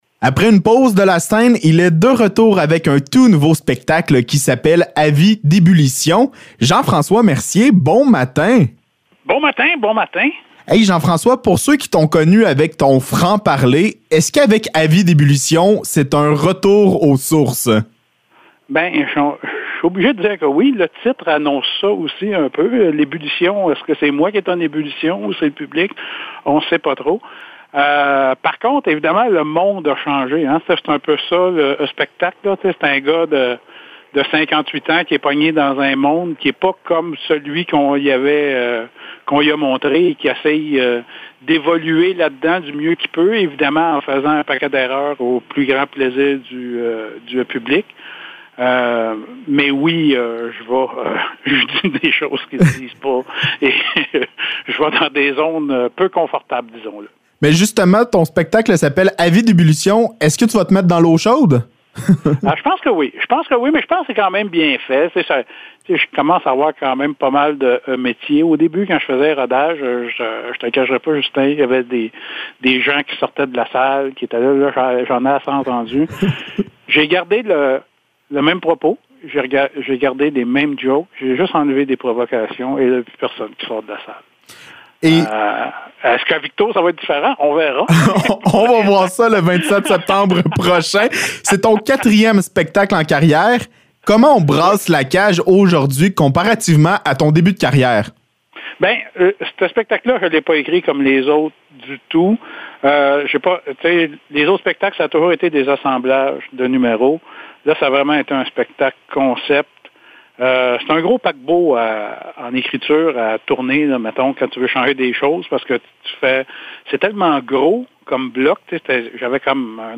Entrevue avec Jean-François Mercier